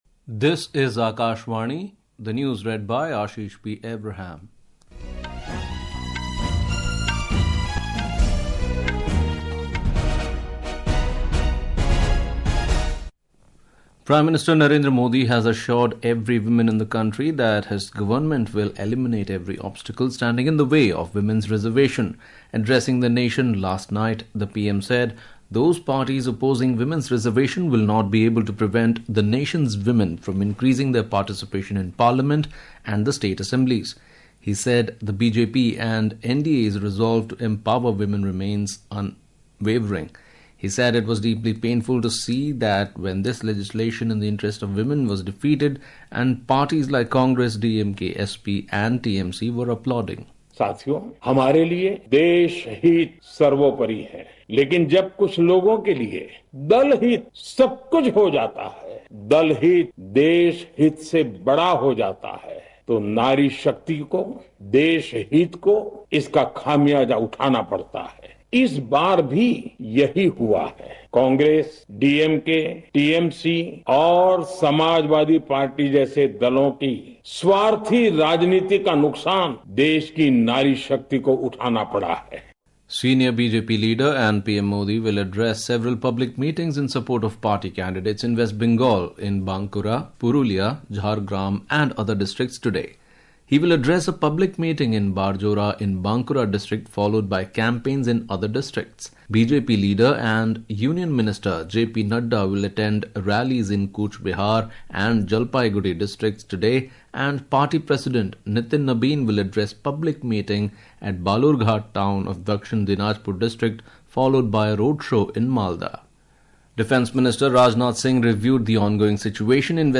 Hourly News